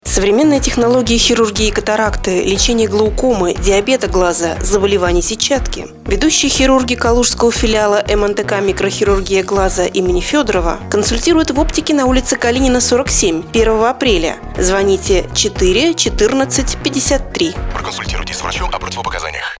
Стартовало размещение рекламы на радиостанции "Европа Плюс" компании "МНТК" в Мценске.